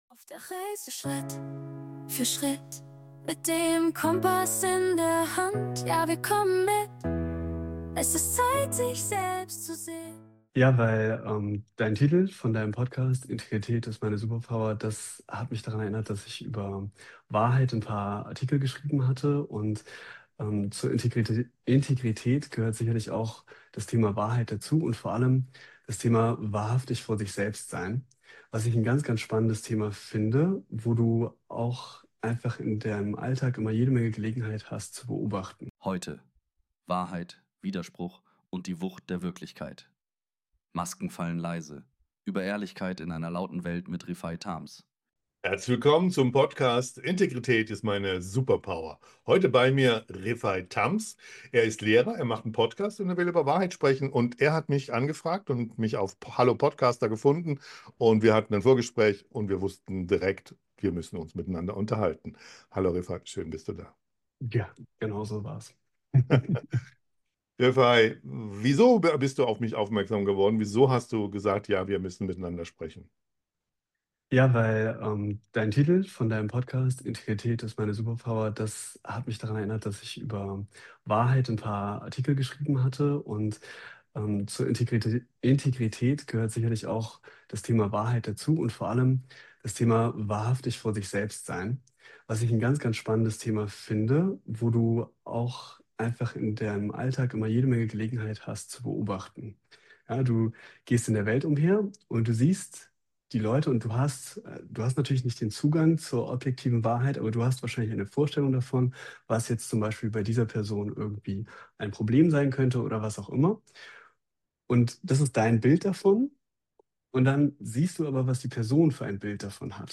Dieses Gespräch ist kein klassisches Interview.